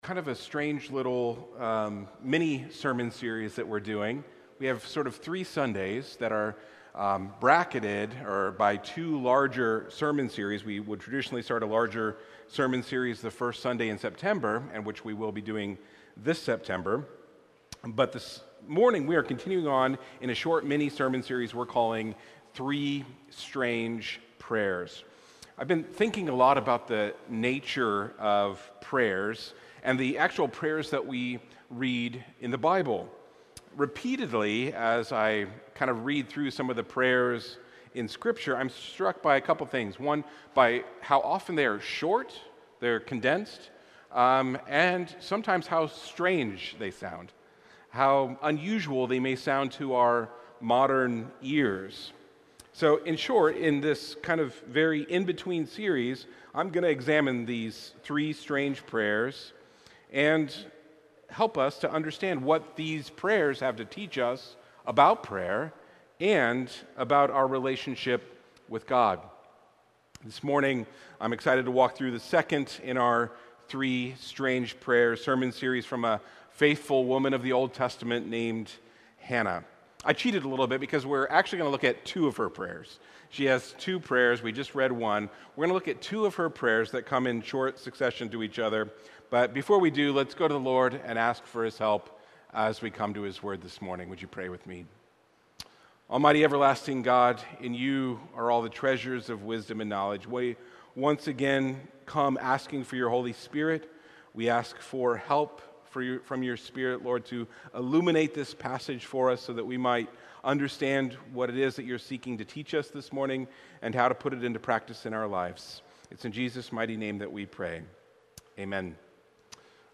The sermon explores Hannah’s pleading prayer, God’s response, and her triumphant second prayer of praise, emphasizing the importance of experiencing our own deepest emotions in our connection to God.